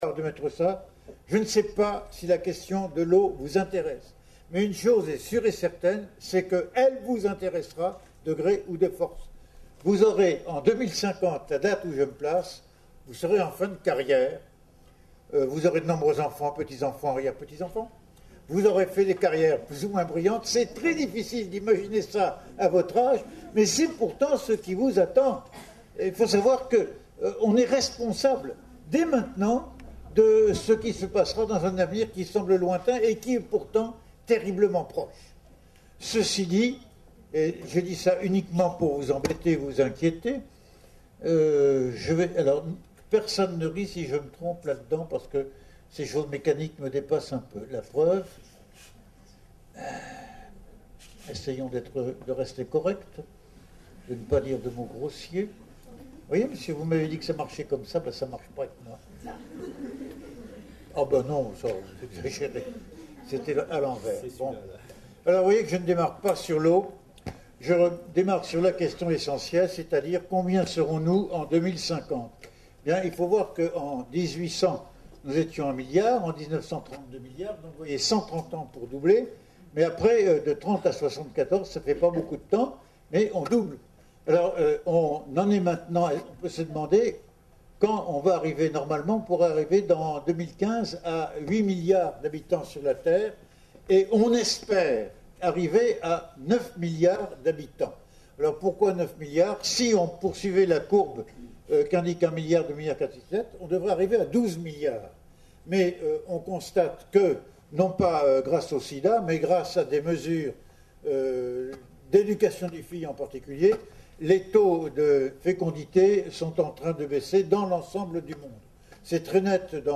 Une conférence de l'UTLS au lycée La question de l'eau en 2050, un drame planétaire prévisible ?